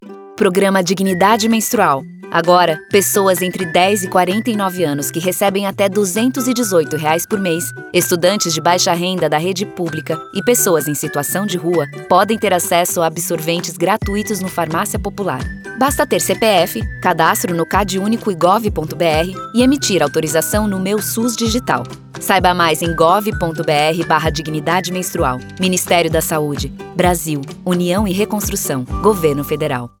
Áudio Spot - Campanha de Dignidade Menstrual - 30seg .mp3